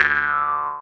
set_snare.ogg